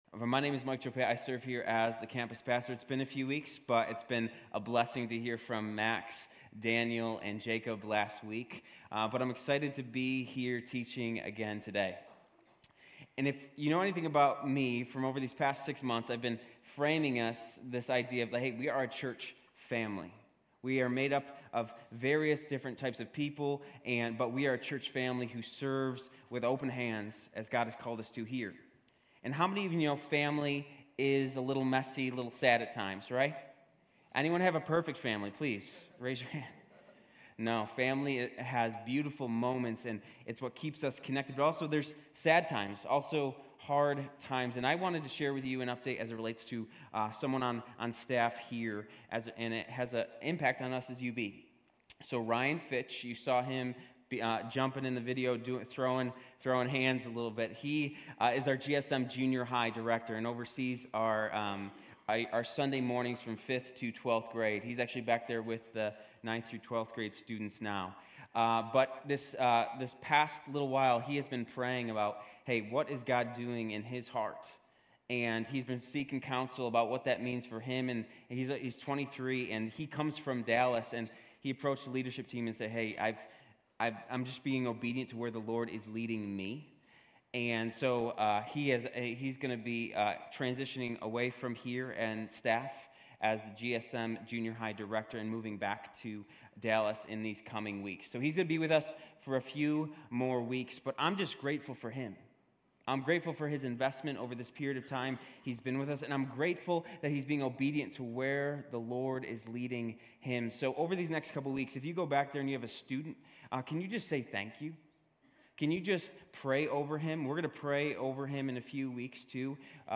Grace Community Church University Blvd Campus Sermons Life in the Vineyard: John 15:12-15 Feb 05 2024 | 00:32:09 1x 00:00 / 00:32:09 Subscribe Share RSS Feed Share Link Embed